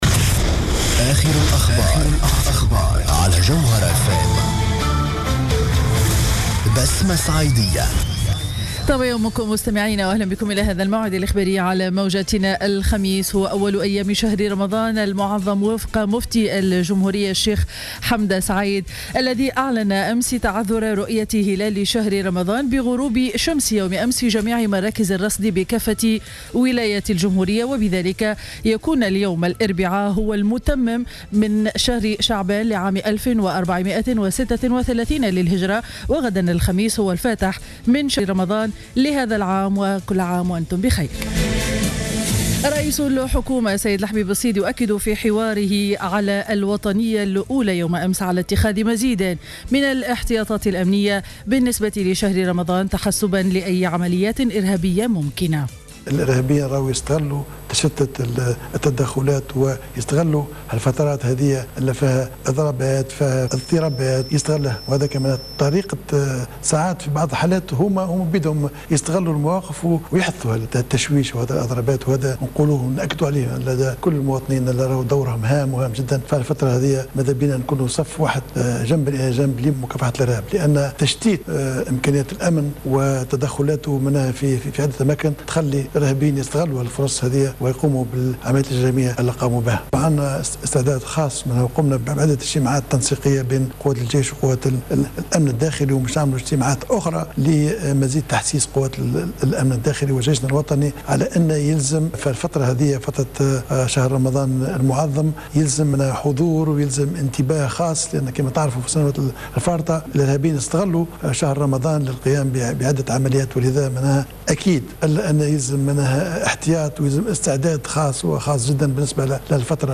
نشرة أخبار السابعة صباحا ليوم الإربعاء 17 جوان 2015